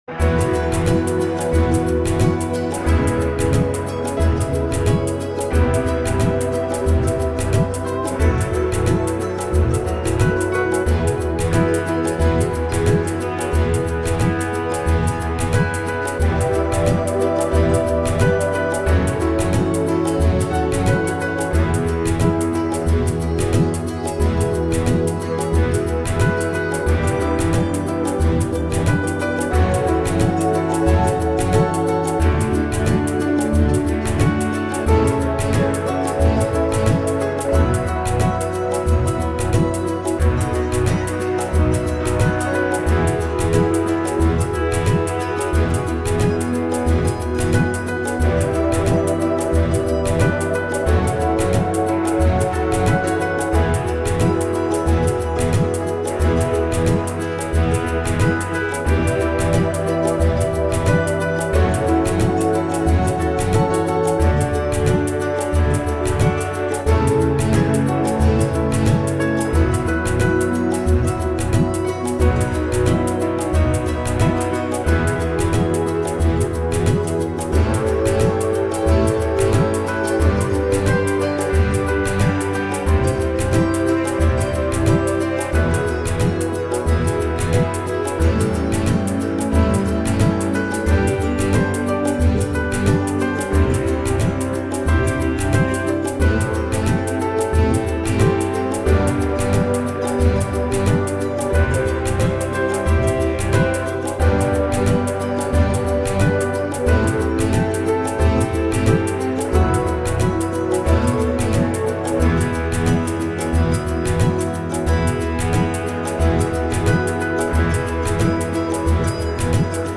My backing was more fun to make than listen back to: